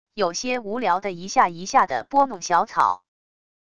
有些无聊的一下一下的拨弄小草wav音频